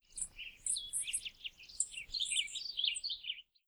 Birds 1.wav